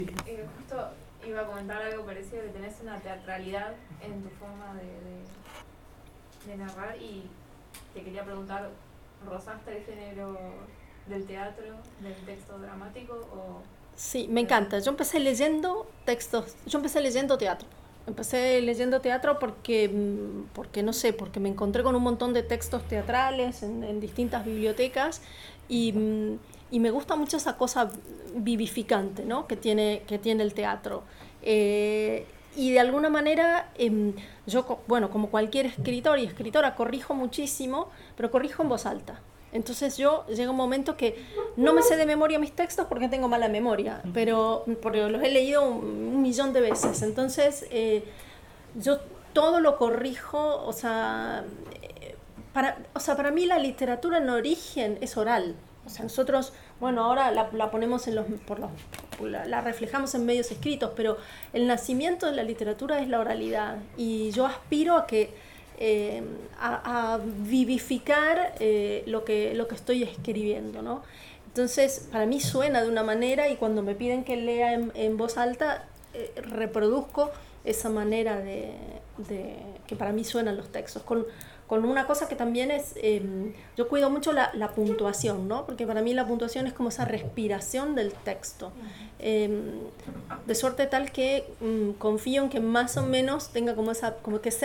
Encuentros literarios en Siegen
Entrevista